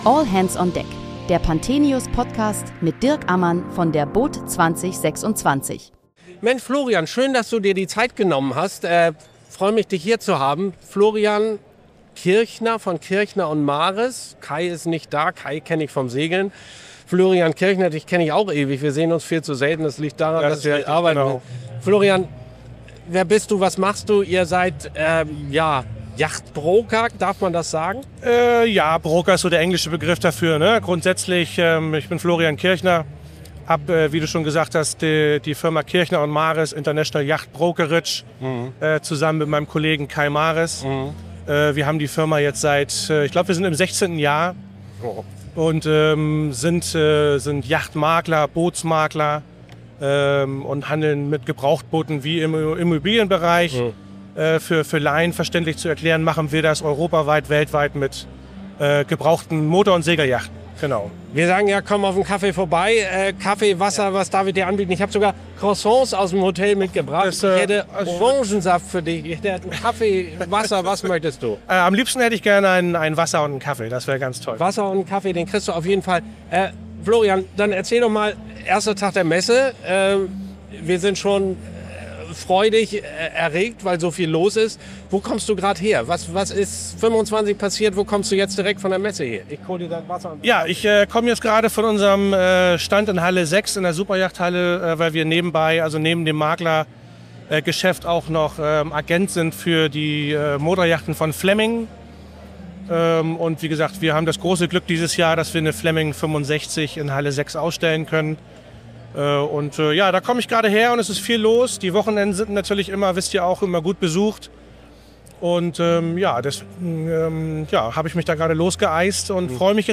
Willkommen zur ersten Folge unseres Messe-Podcasts von der boot Düsseldorf 2026. Jeden Tag sprechen wir mit Gästen aus der Branche über aktuelle und kontroverse Themen rund um den Wassersport.